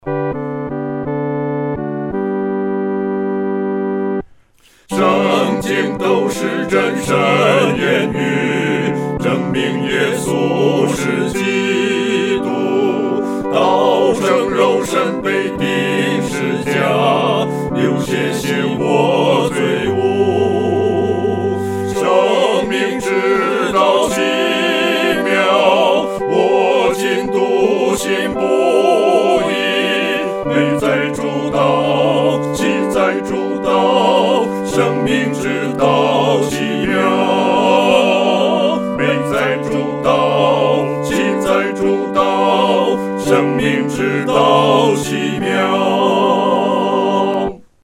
合唱（四声部）